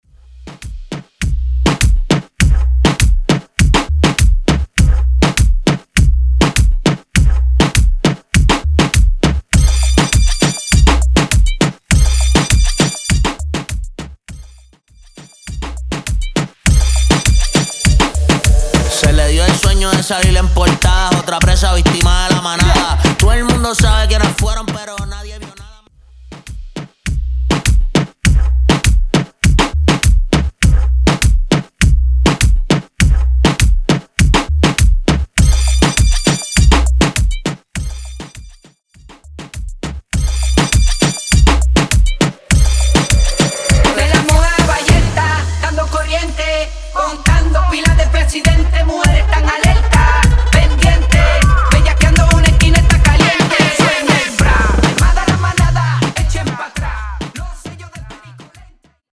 Intro Dirty, Acapella Viral Dirty